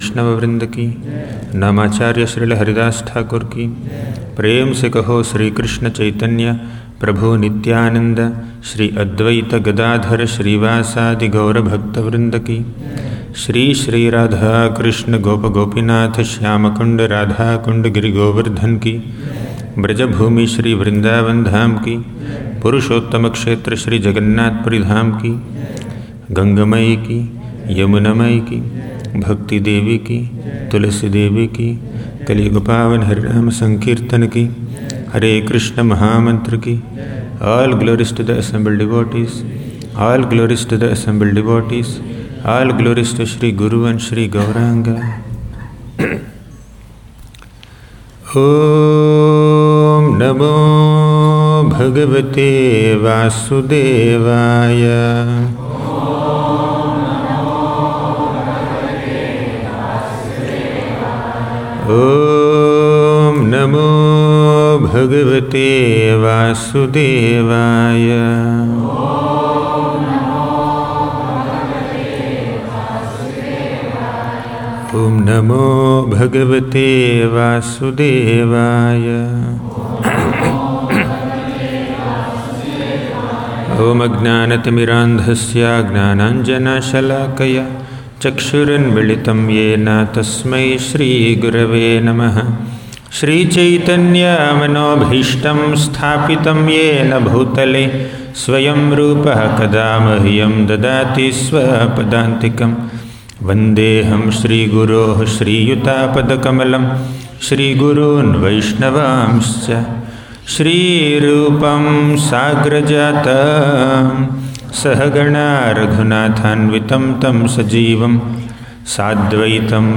Saranagati Retreat - Brhad Bhagavatamrta 1 - a lecture